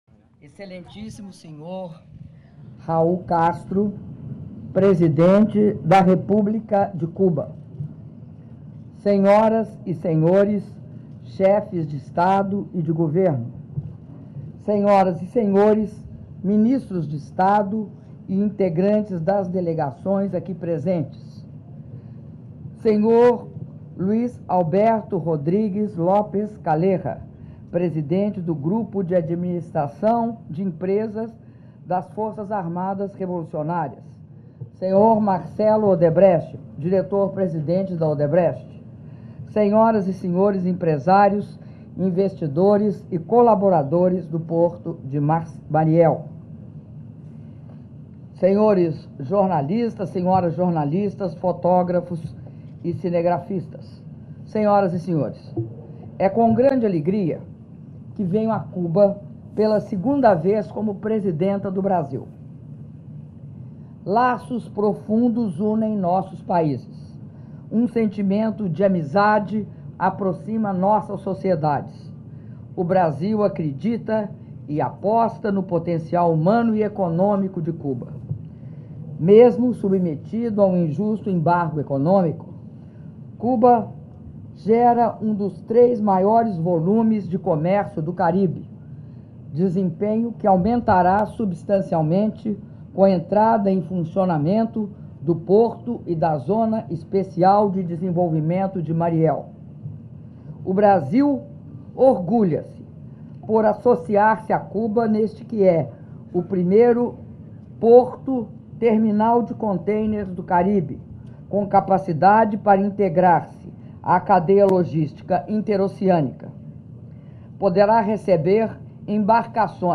Áudio do discurso da Presidenta da República, Dilma Rousseff, durante cerimônia de inauguração do Porto de Mariel (05min56s)